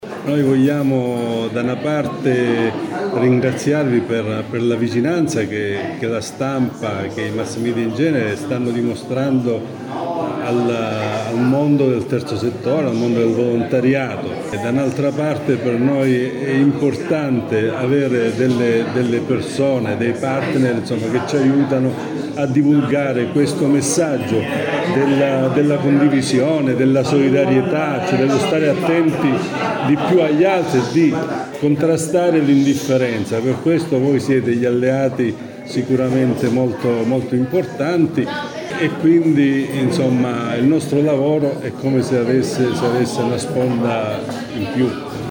Così il giornalista 𝑫𝒐𝒎𝒆𝒏𝒊𝒄𝒐 𝑰𝒂𝒏𝒏𝒂𝒄𝒐𝒏𝒆 nel corso dell’incontro organizzato a Villa Rendano dal CSV con la stampa locale in occasione di Cosenza capitale italiana del volontariato 2023.